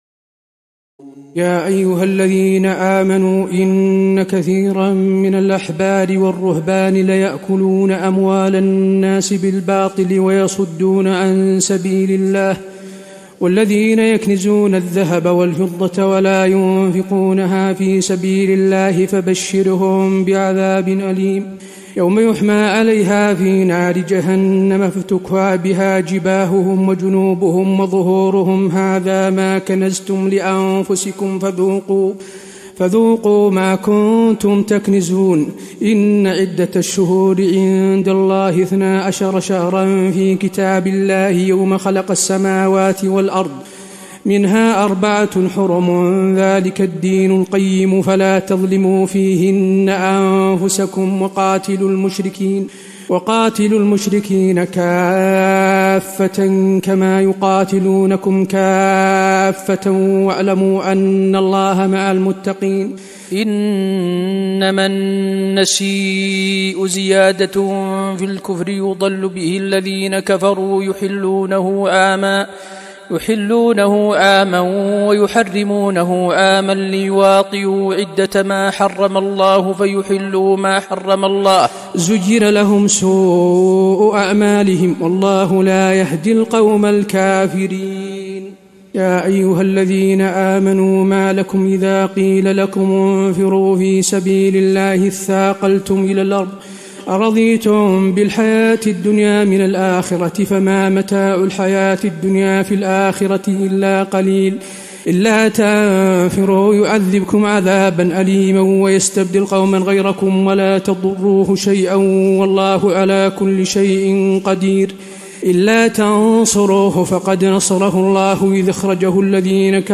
تراويح الليلة التاسعة رمضان 1435هـ من سورة التوبة (34-89) Taraweeh 9 st night Ramadan 1435H from Surah At-Tawba > تراويح الحرم النبوي عام 1435 🕌 > التراويح - تلاوات الحرمين